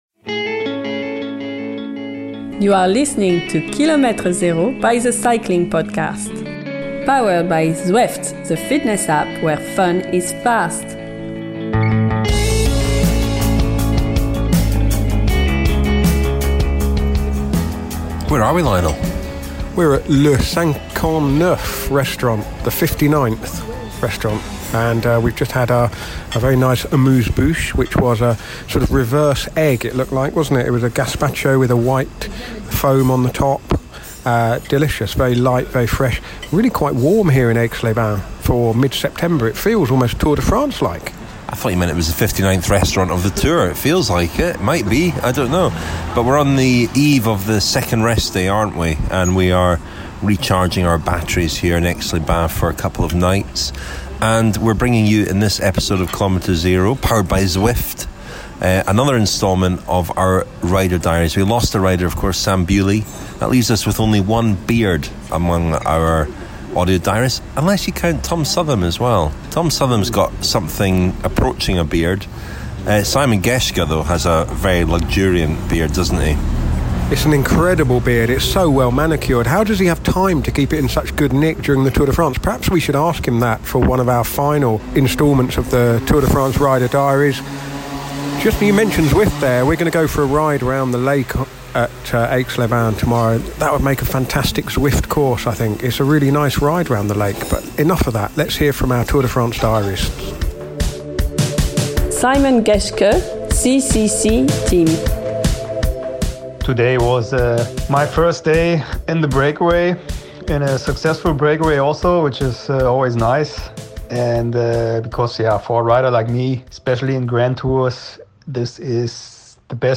Despatches from our team of audio diarists will appear throughout our Tour de France coverage as well as in Kilometre 0.